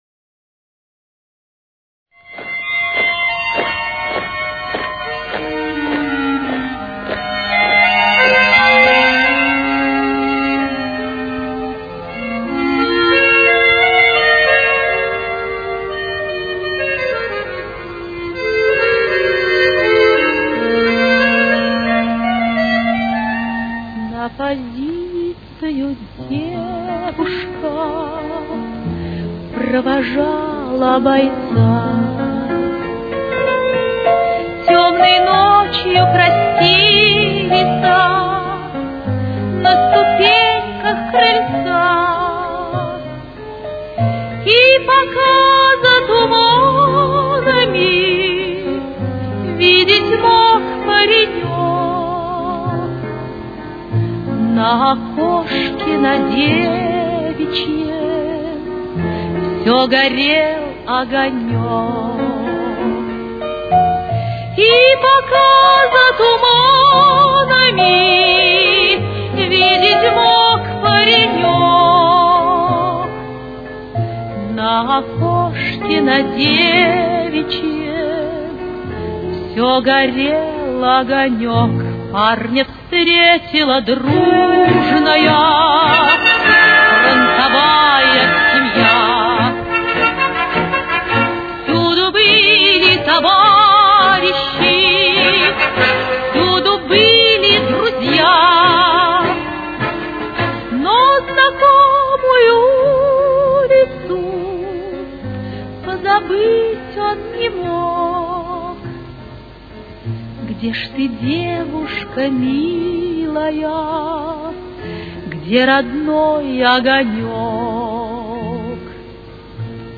Темп: 102.